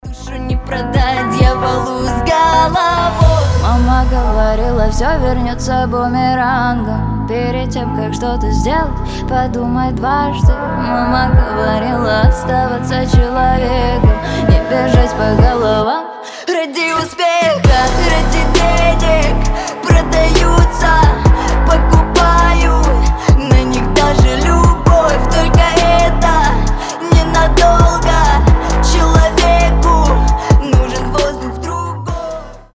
поп , рэп